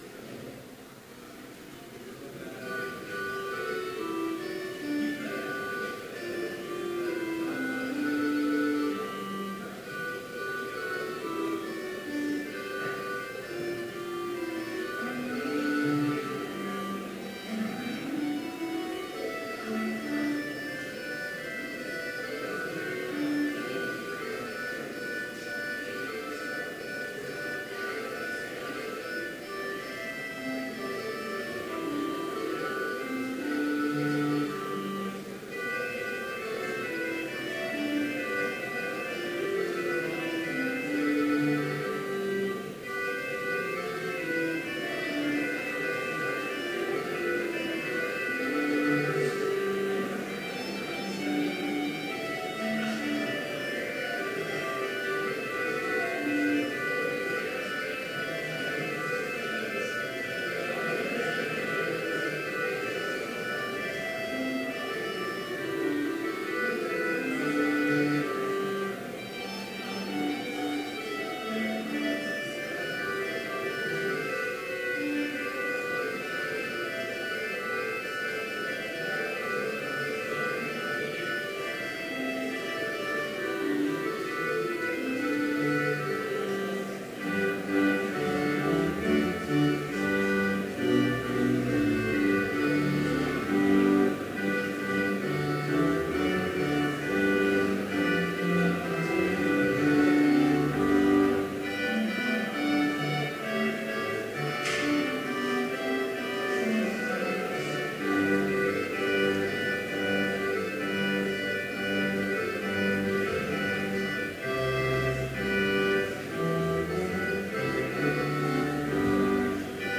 Complete service audio for Chapel - March 24, 2017